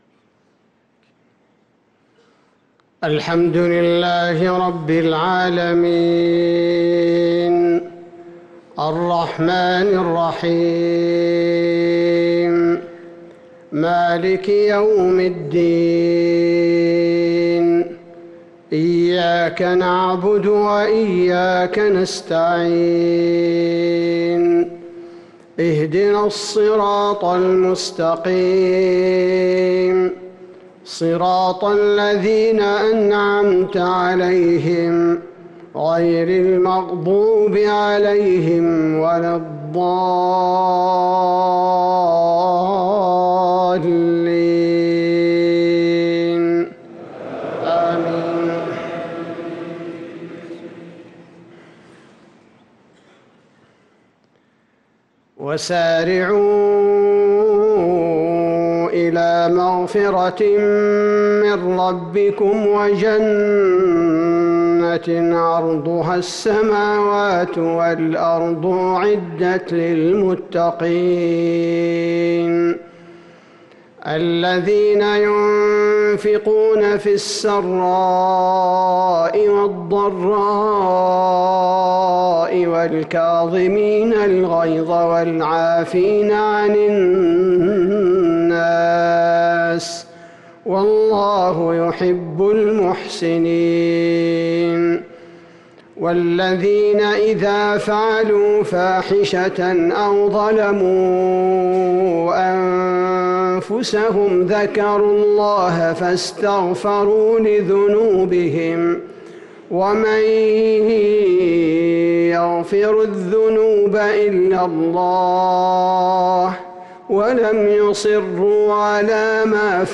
صلاة المغرب للقارئ عبدالباري الثبيتي 29 ربيع الأول 1445 هـ
تِلَاوَات الْحَرَمَيْن .